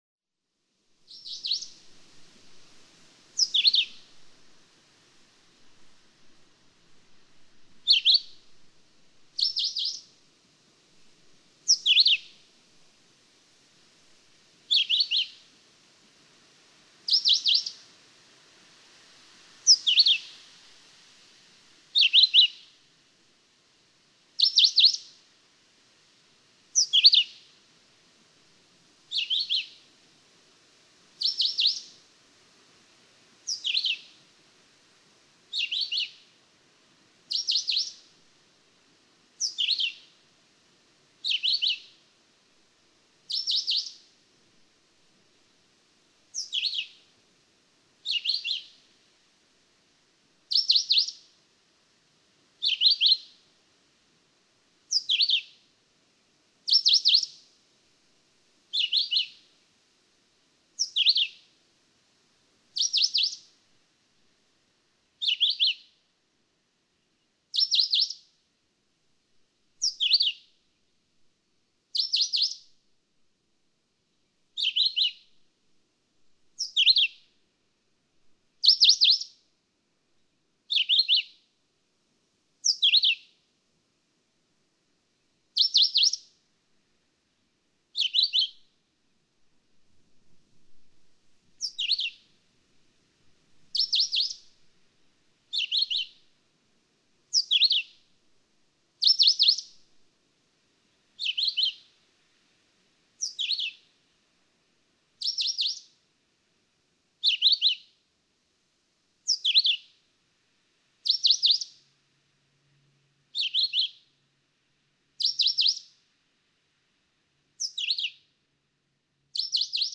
Philadelphia vireo
Count as you did for the red-eyed vireo and you inevitably count to only four, five, or six before you hear a particular song repeated.
♫432. Example 3. June 15, 2013. Ear Falls, Ontario. (2:25)
432_Philadelphia_Vireo.mp3